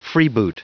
Prononciation du mot freeboot en anglais (fichier audio)
Prononciation du mot : freeboot